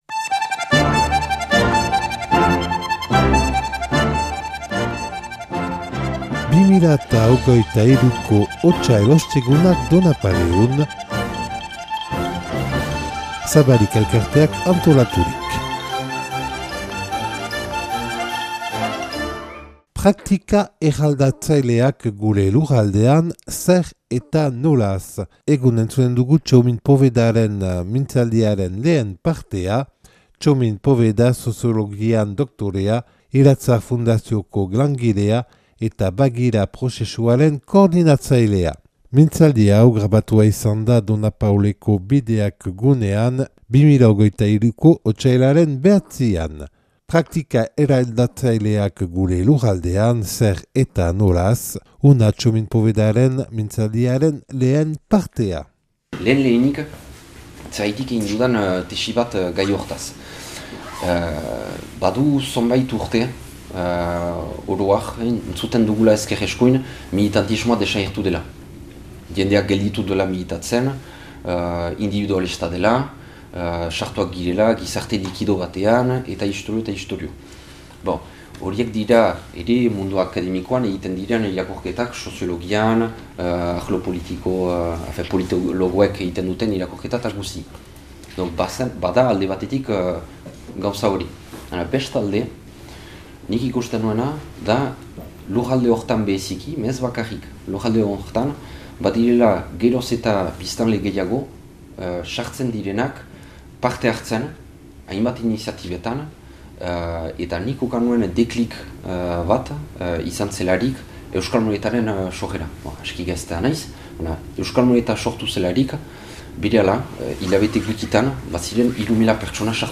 Donapaleun grabatua 2023. otsailaren 9an. Zabalik elkarteak antolaturik.